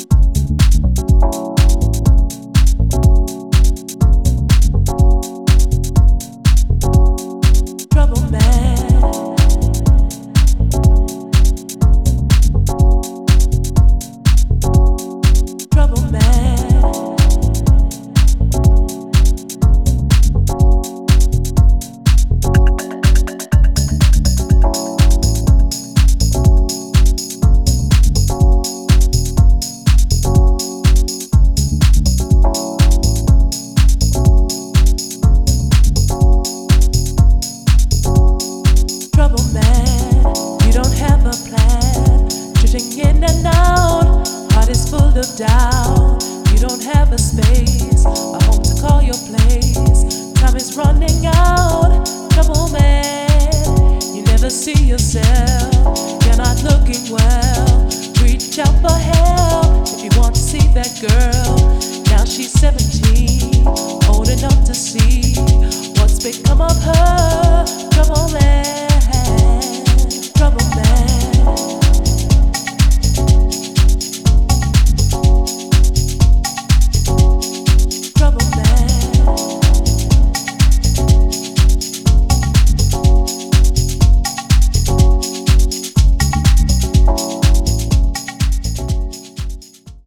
deep house, soul, and jazz-funk